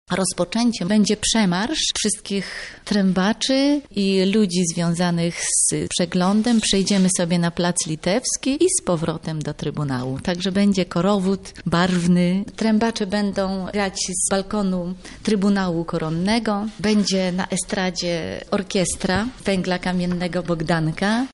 konferansjer przeglądu